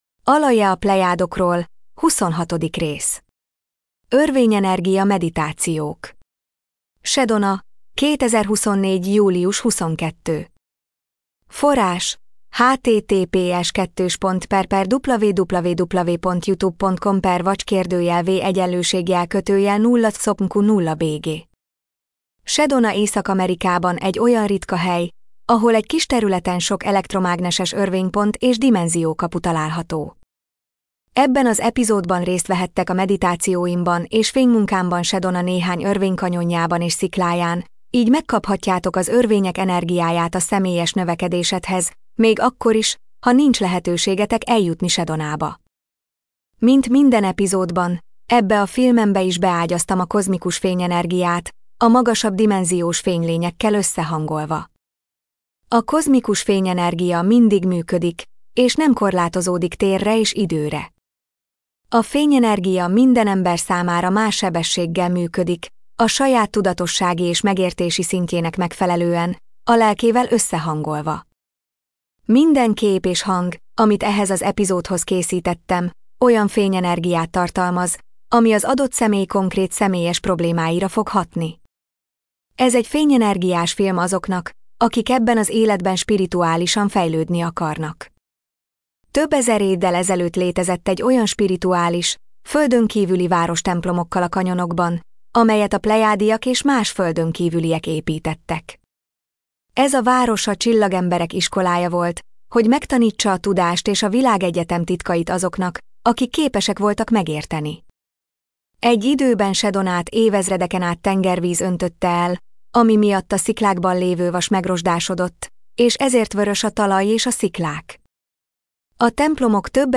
MP3 gépi felolvasás